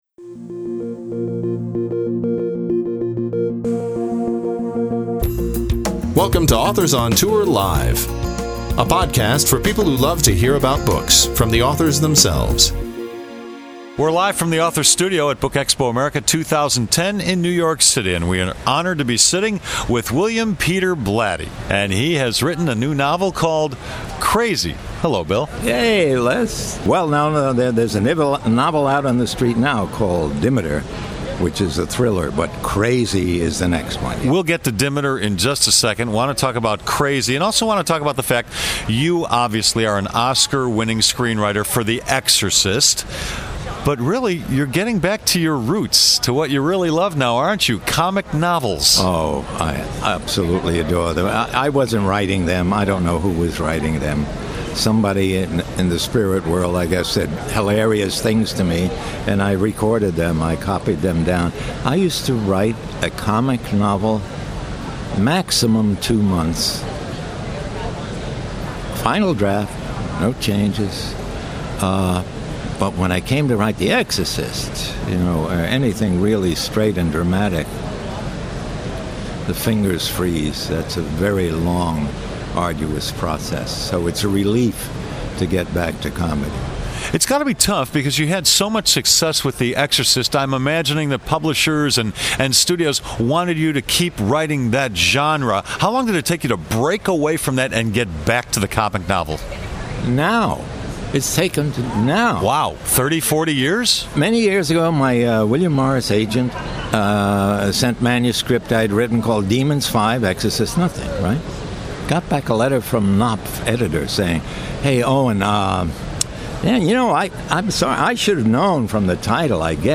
This podcast was recorded at BookExpo America 2010 in New York City.